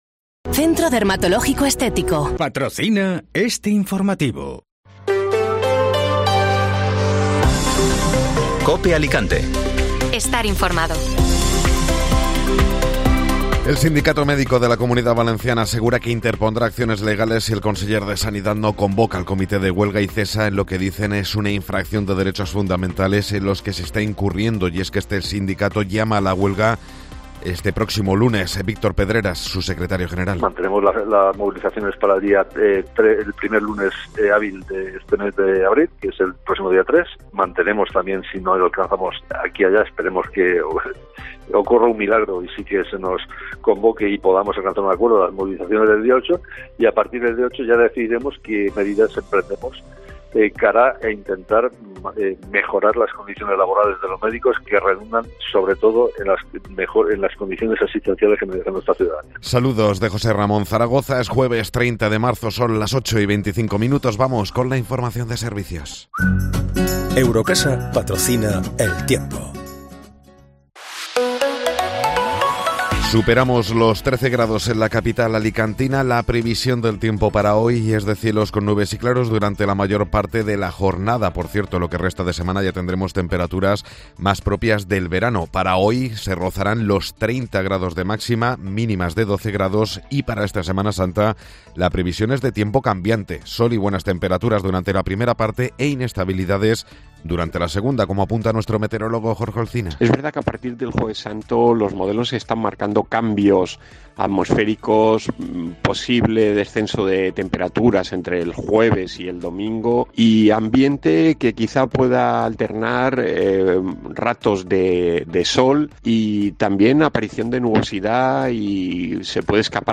Informativo Matinal (Jueves 30 de Marzo)